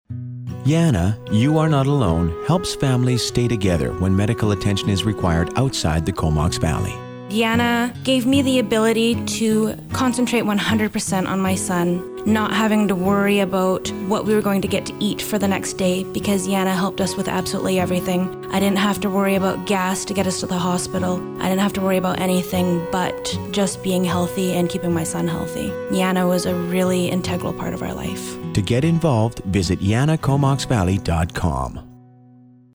Listen to what this YANA parent has to say and read more about how YANA eases the burden here.
YANA-Full-Testimonial-1_Final.mp3